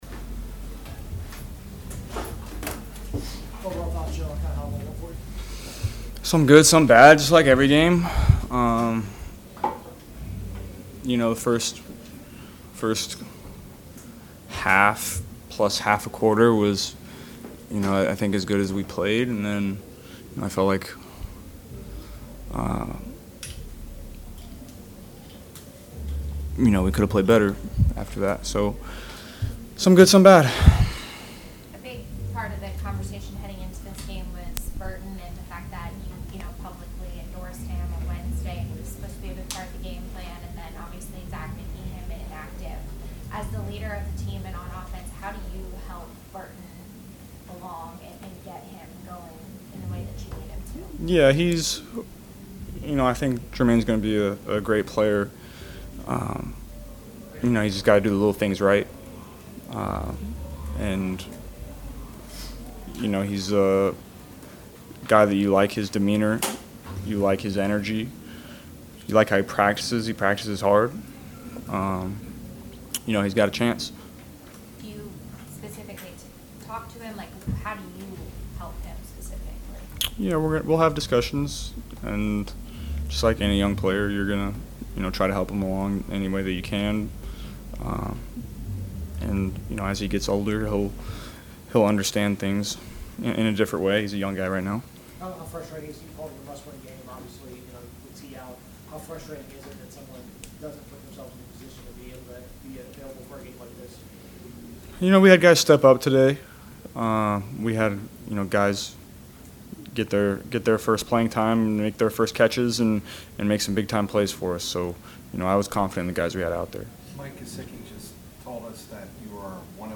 Bengals QB Joe Burrow meets with media after 41-24 win over Raiders; likes win but said team could’ve done more